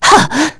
Scarlet-vox-Jump.wav